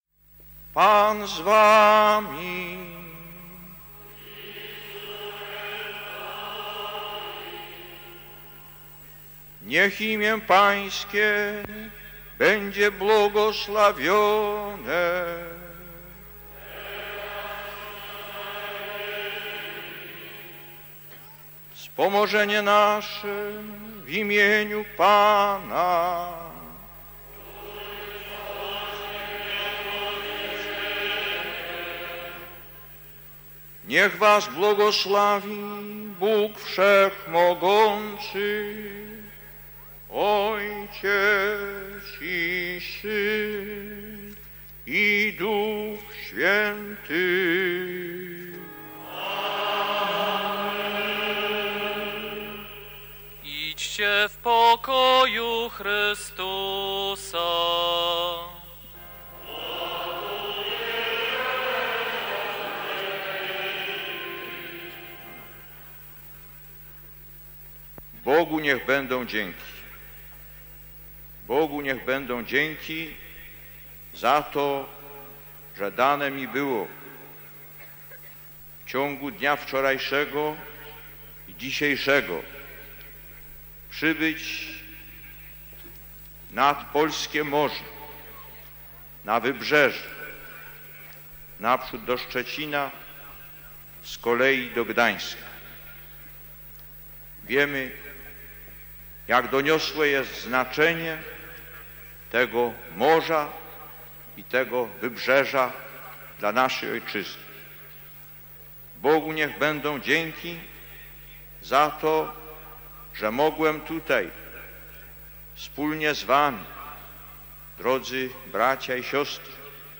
Spotkanie Jana Pawła II z ludźmi pracy w Gdańsku-Zaspie: relacja [dokument dźwiękowy] - ${res.getProperty('base.library.full')}
Homilia Jana Pawła II do ludzi pracy